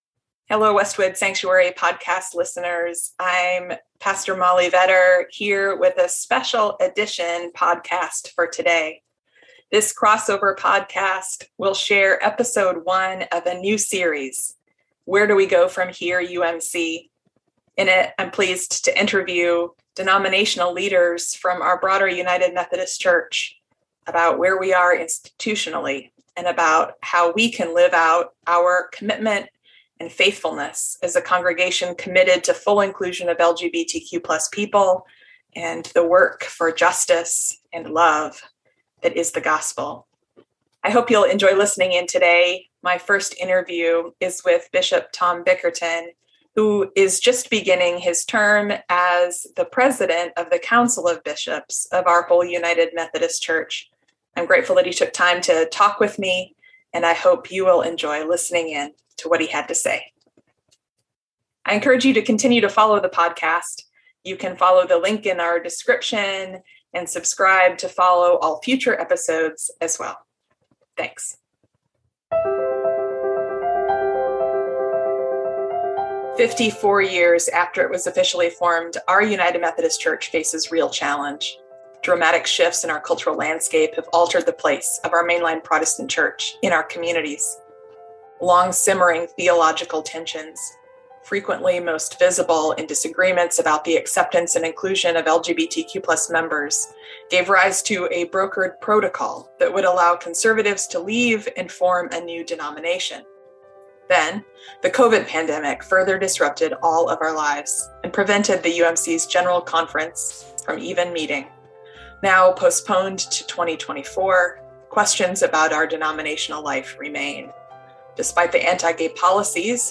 a weekly podcast featuring conversations